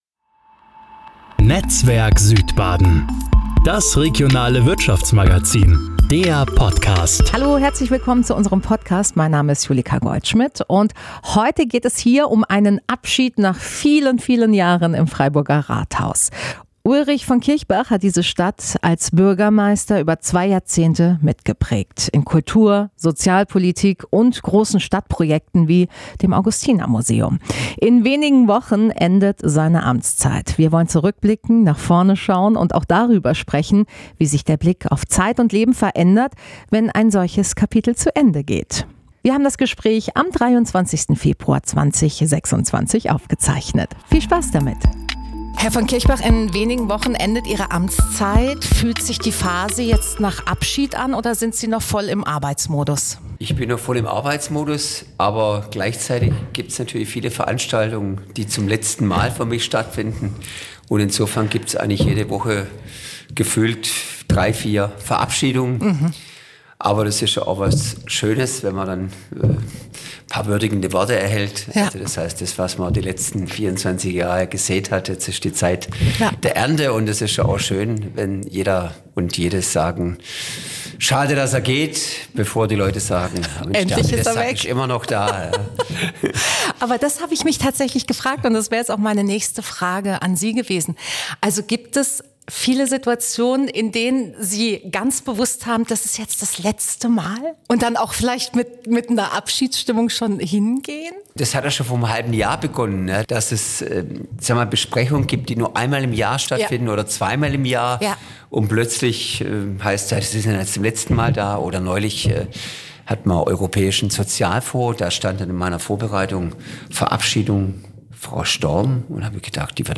Dieses Mal zu Gast: Ulrich von Kirchbach, dessen Amtszeit als Bürgermeister in Freiburg in wenigen Wochen nach mehr als zwei Jahrzehnten endet.